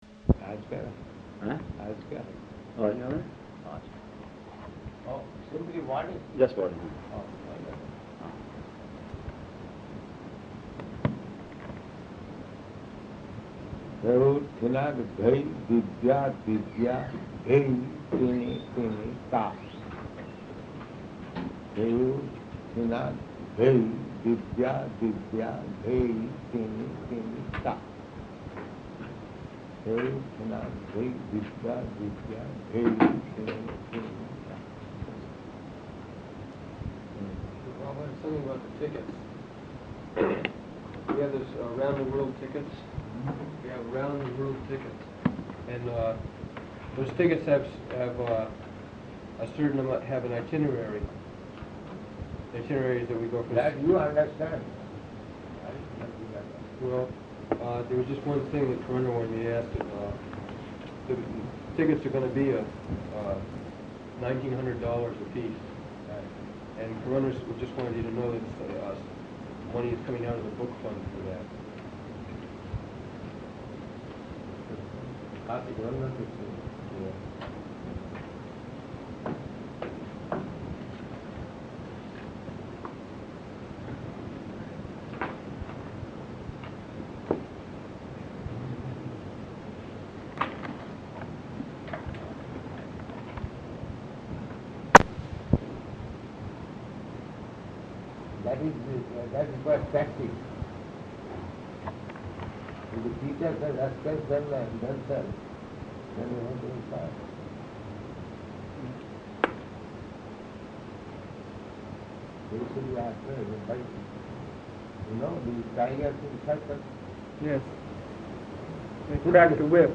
Room Conversation
Location: Los Angeles